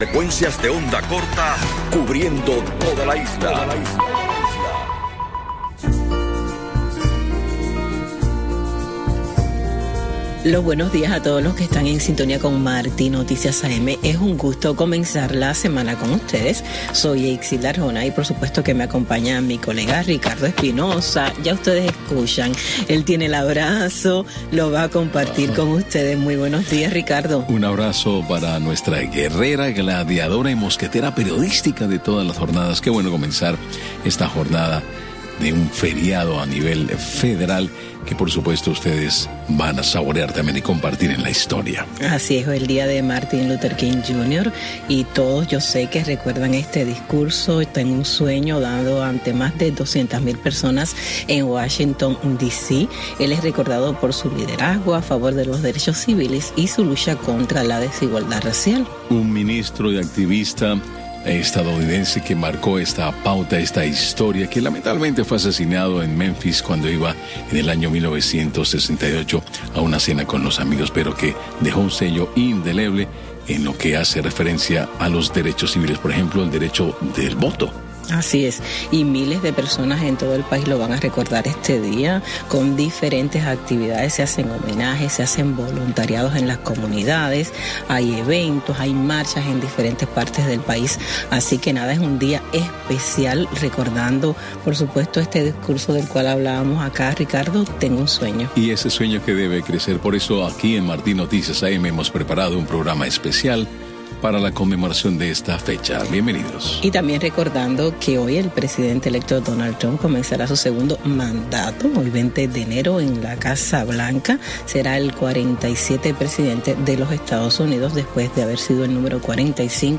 Revista informativa con los últimos acontecimientos ocurridos en Cuba y el mundo. Con entrevistas y temas de actualidad relacionados a la política, la economía y de interés general.